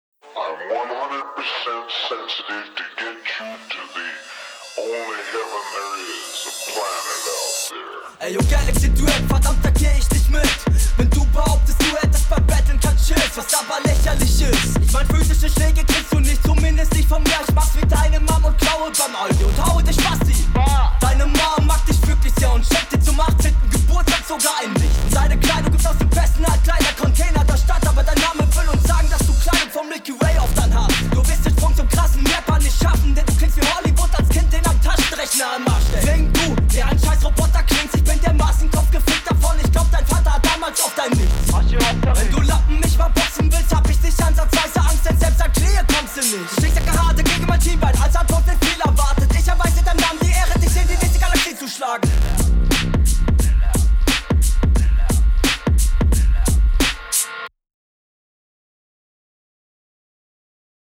Stimme super eingesetzt, aber etwas zu leise.
Sehr geiler Beat vorneweg.